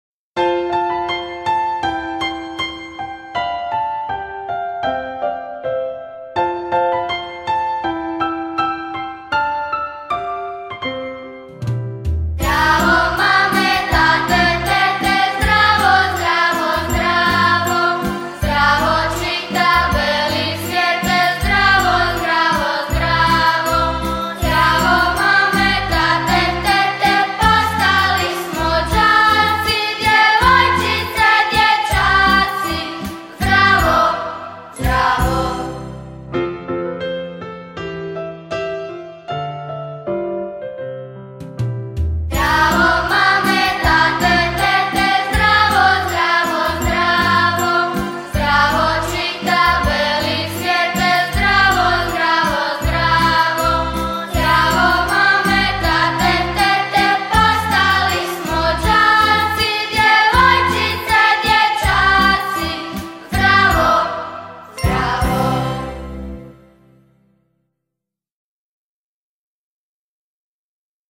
Zapjevaj
himna_prvoskolaca_-_zbor.mp3